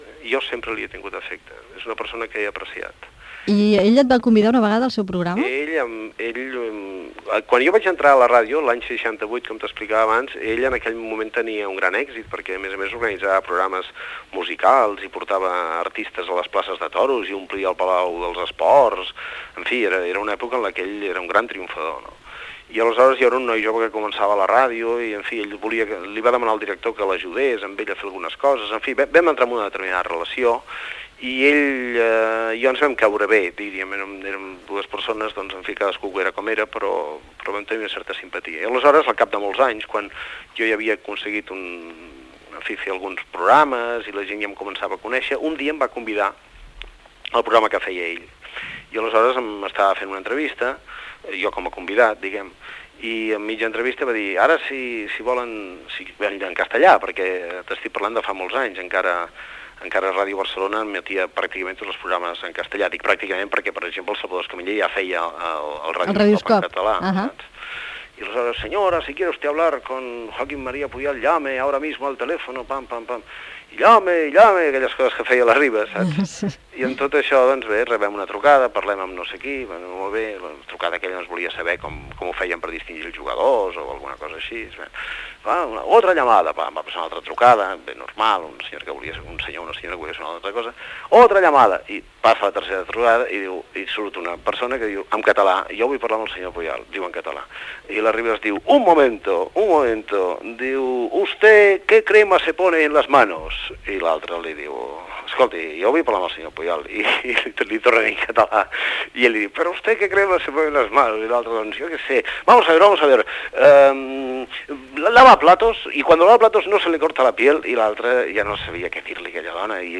Programa especial sobre anècdotes a la ràdio. Trucada a Joaquim Maria Puyal que explica un moment radiofònic junt a Luis Arribas Castro, a Ràdio Barcelona.